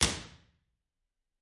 脉冲响应 " Studio B Right
描述：中田纳西州立大学的B工作室的脉冲响应。这个包里有这个房间的4个脉冲，有不同的话筒位置，用于替代方向性提示。
标签： 响应 逆向工程＆ B 周围环境 脉冲 IR
声道立体声